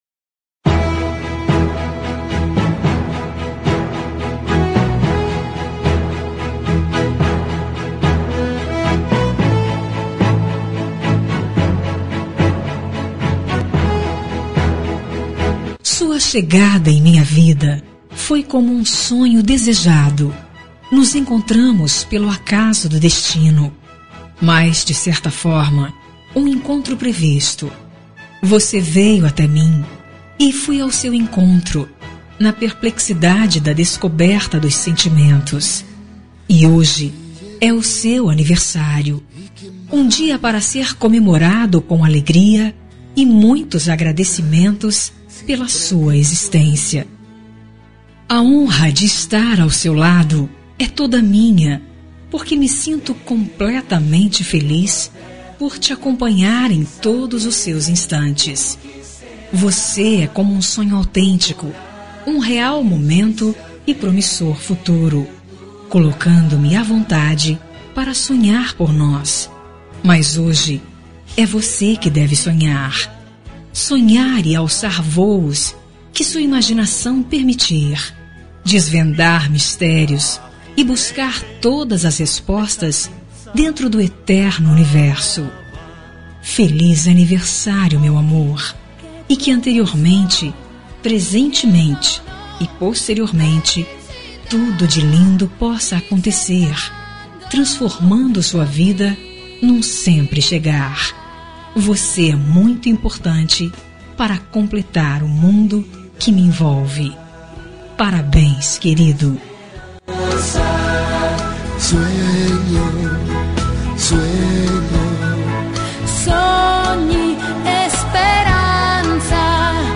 Aniversário Romântico | Voz Feminina